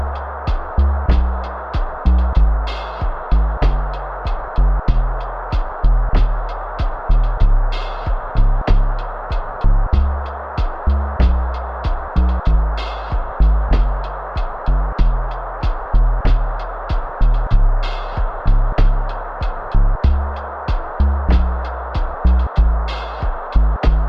Did not expect this, but just realized the microphone is the ultimate noise music mic with the built in FX.